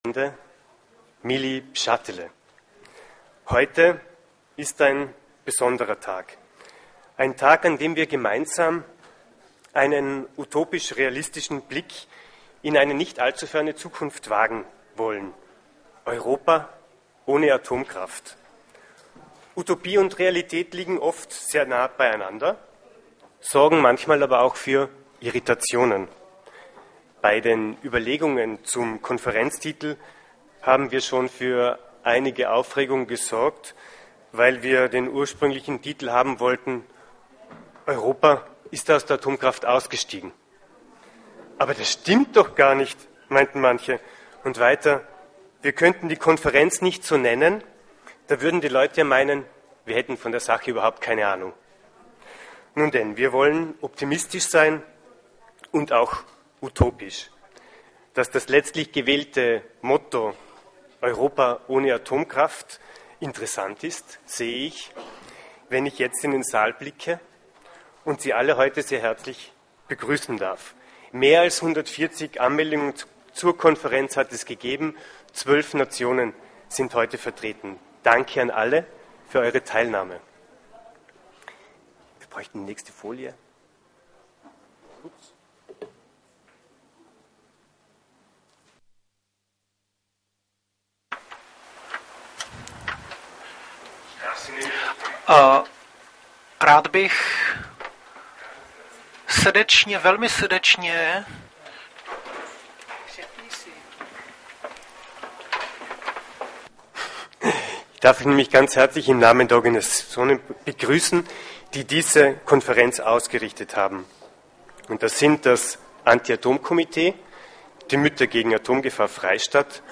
Vormittag Eröffnungs-Keynote Franz Alt